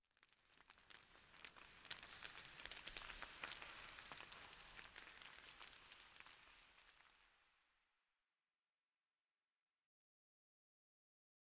Minecraft Version Minecraft Version 1.21.5 Latest Release | Latest Snapshot 1.21.5 / assets / minecraft / sounds / ambient / nether / basalt_deltas / long_debris1.ogg Compare With Compare With Latest Release | Latest Snapshot
long_debris1.ogg